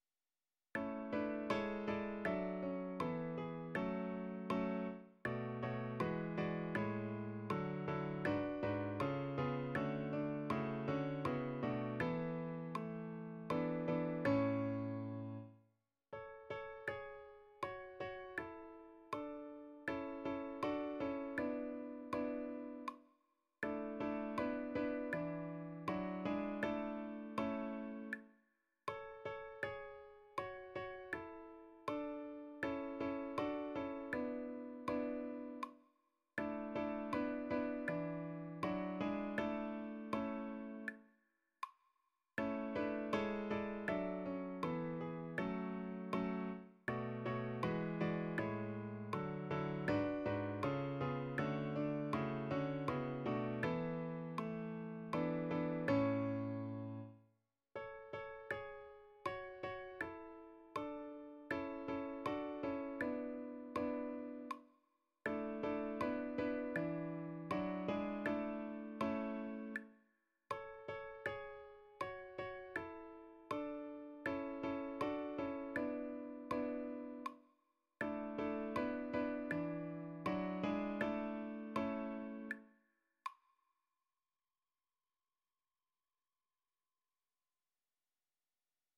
R�p�tition de la pi�ce musicale